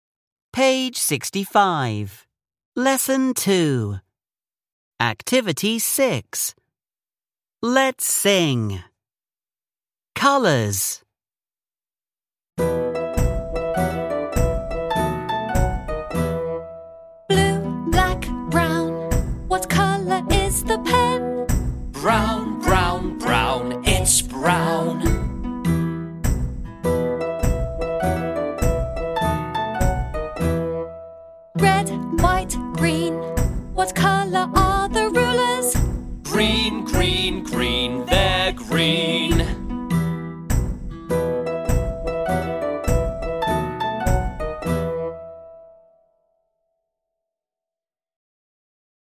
Let’s sing. (Chúng ta cùng hát.)